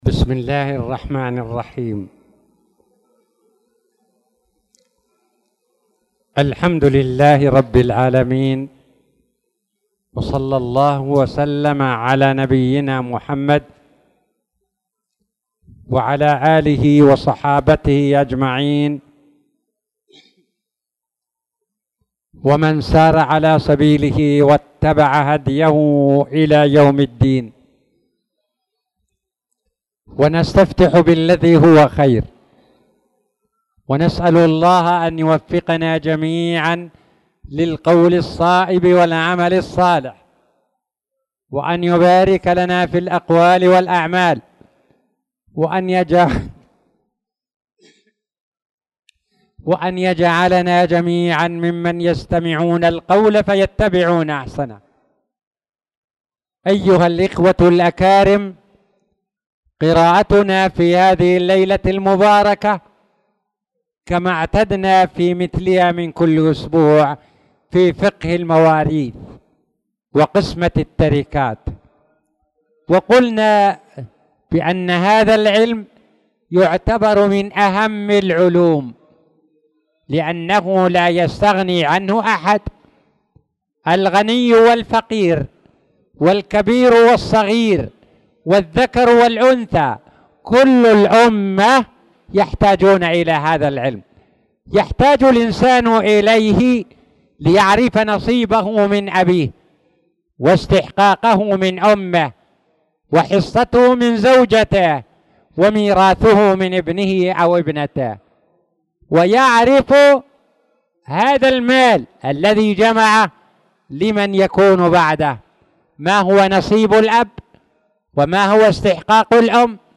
تاريخ النشر ٢ شعبان ١٤٣٧ هـ المكان: المسجد الحرام الشيخ